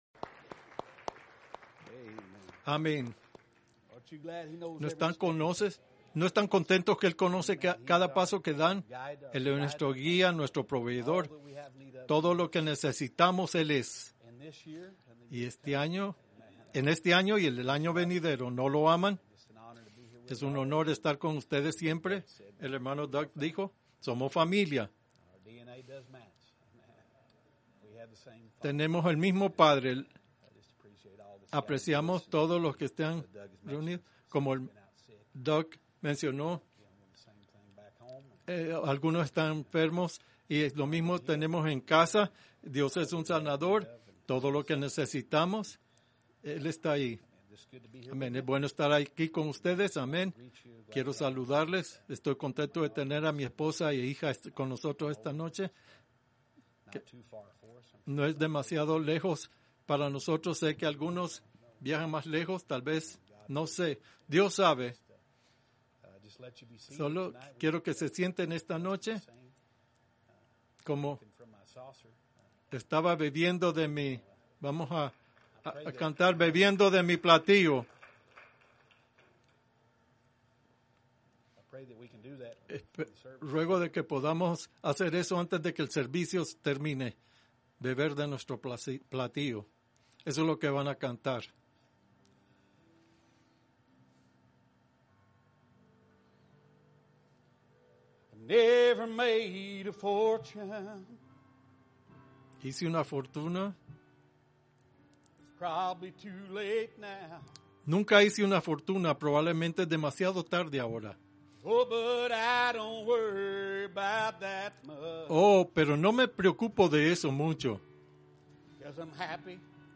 Servicios de Vigilia